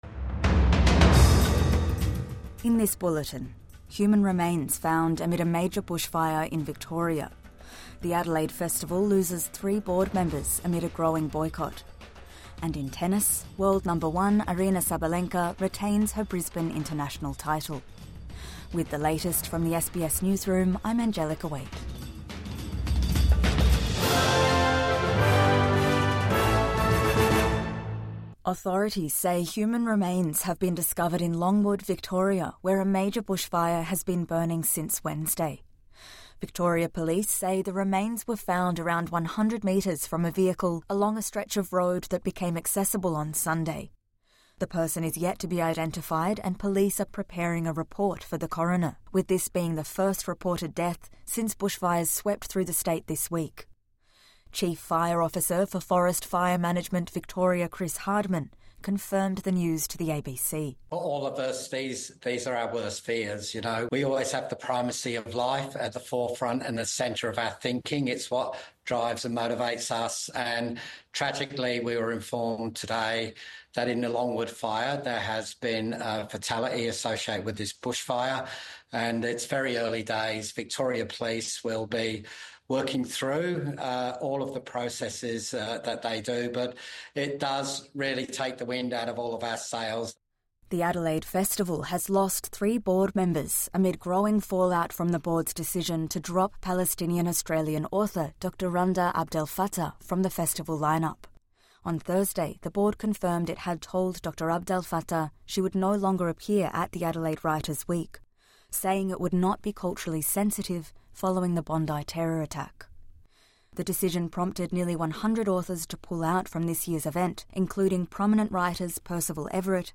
Human remains found amid a major bushfire in Victoria | Morning News Bulletin 12 January 2026